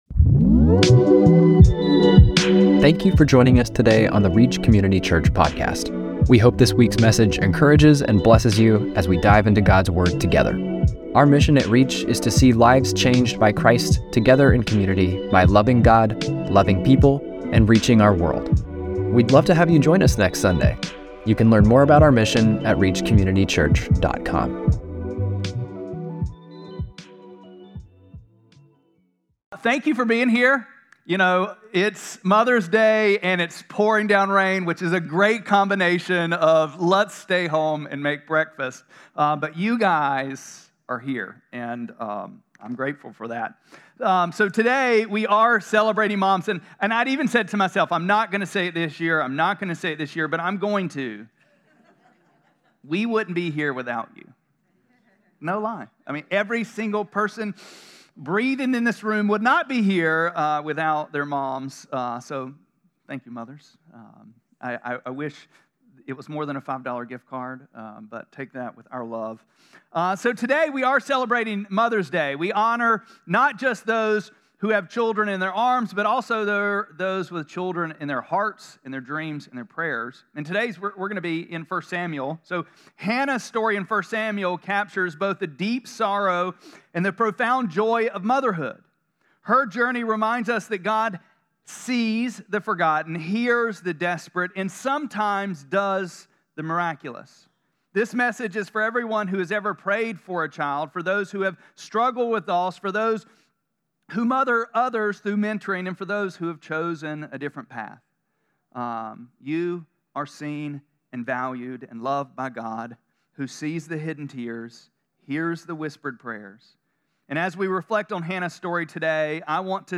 5-11-25-Sermon.mp3